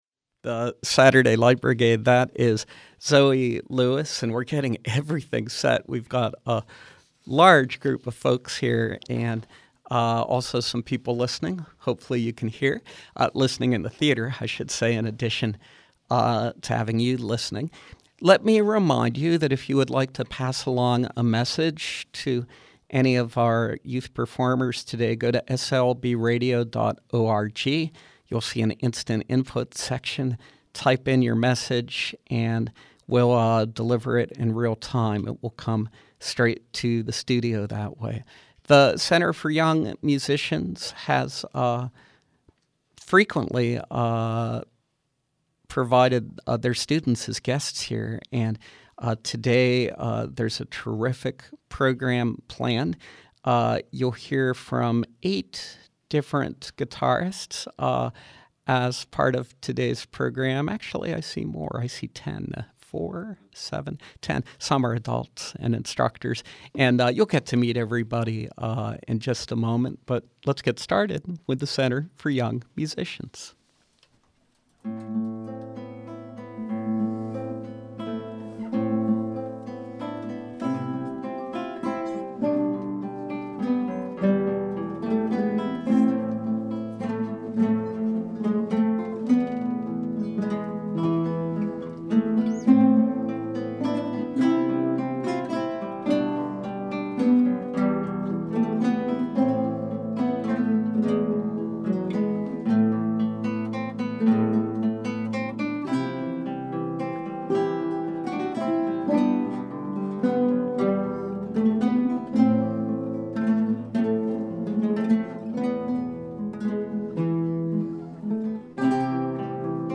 From 5/18/13: Guitarists from the Center for Young Musicians